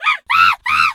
monkey_2_scream_04.wav